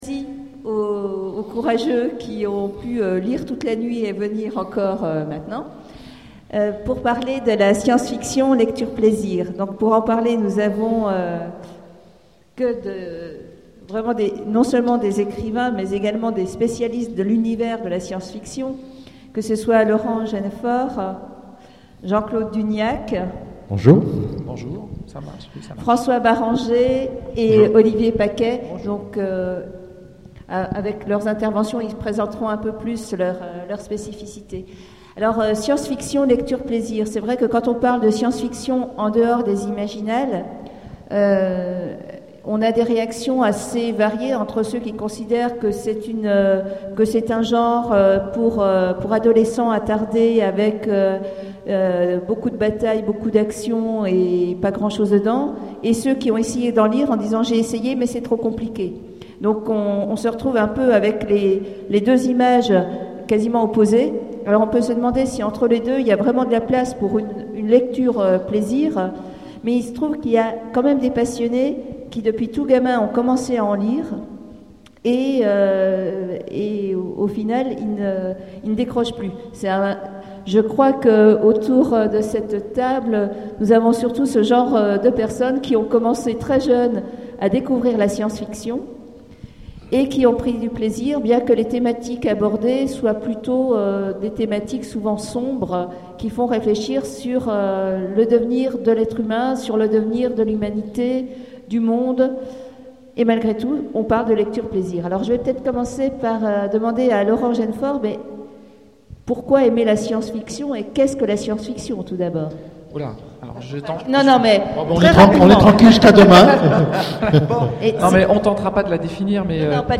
Imaginales 2014 : Conférence La science fiction, une lecture plaisir